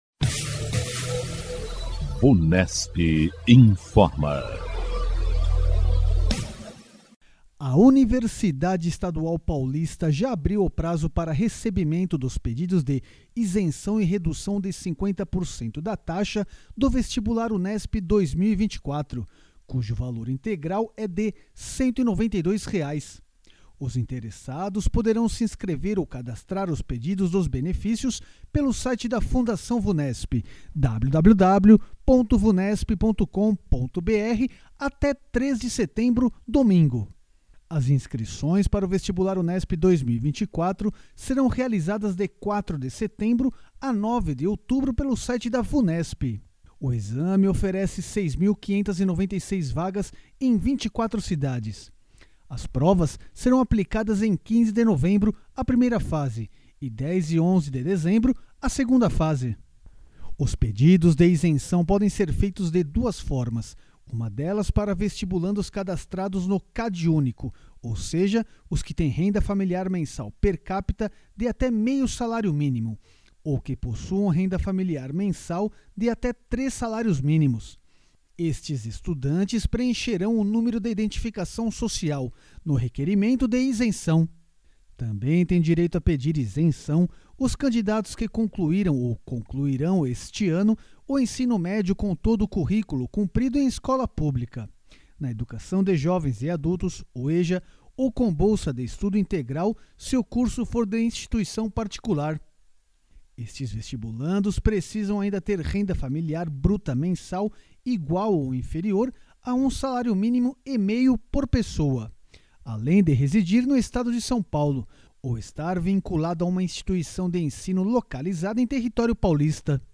A equipe de jornalistas da Vunesp apresenta as últimas informações sobre concursos, vestibulares e avaliações feitas pela Instituição.